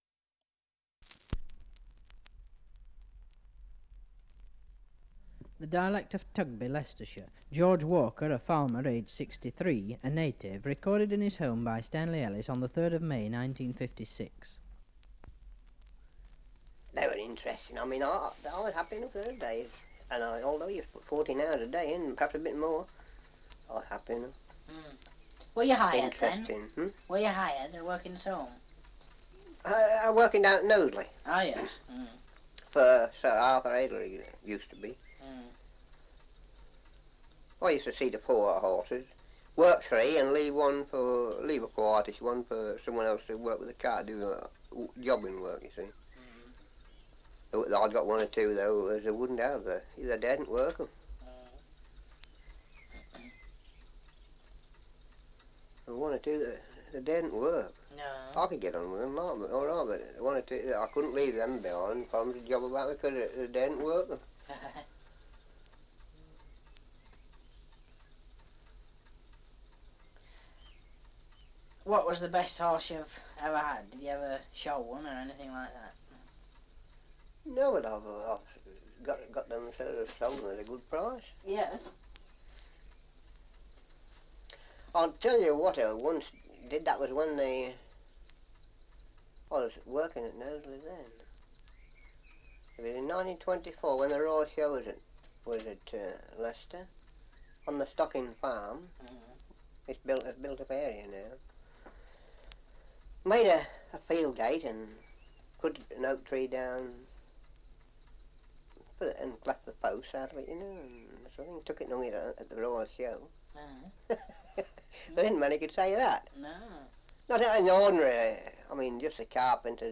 Survey of English Dialects recording in Goadby, Leicestershire
78 r.p.m., cellulose nitrate on aluminium